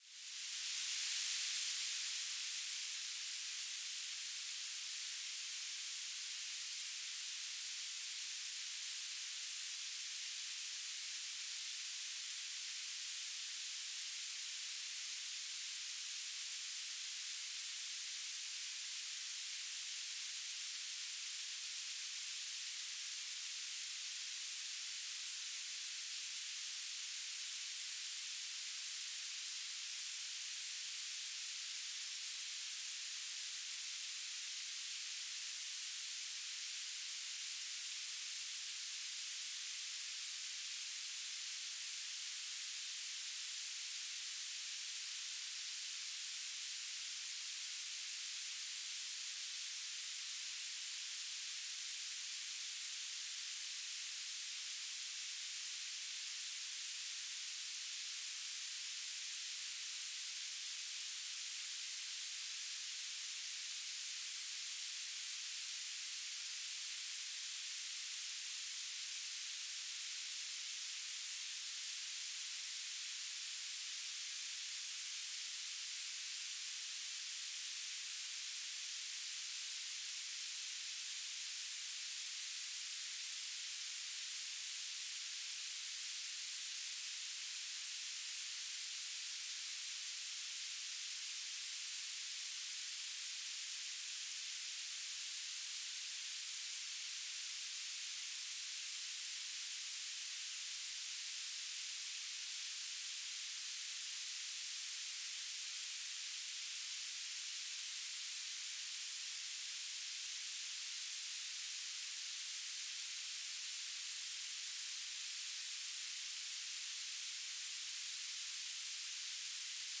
"transmitter_description": "Mode U - BPSK1k2 - Beacon",